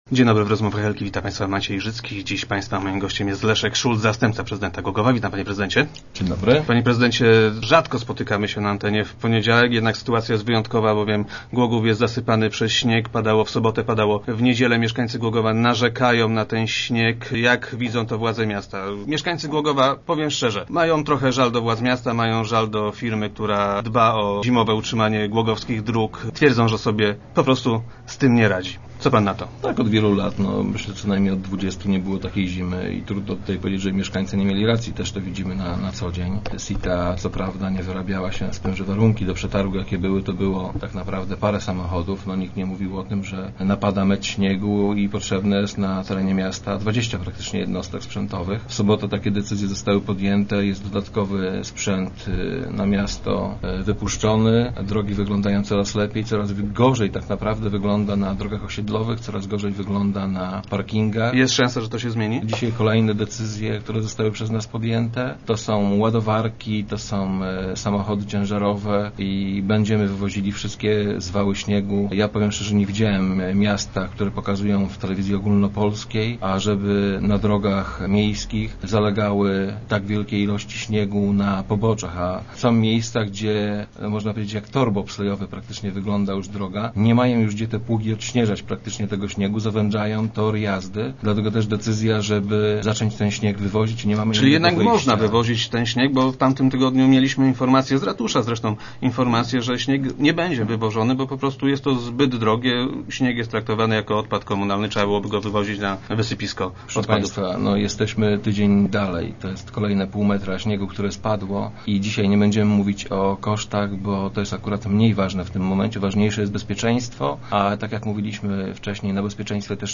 - Jesteśmy tydzień dalej. To jest kolejne pół metra śniegu, które spadło. Dzisiaj nie będziemy więc mówić o kosztach, bo to jest akurat mniej ważne w tej chwili. Pojawiły się więc dodatkowe dyspozycje dla GPK Sita. Pojawił się dodatkowy sprzęt który wyjeżdża dzisiaj na miasto - powiedział wiceprezydent Szulc, który był dziś gościem Rozmów Elki.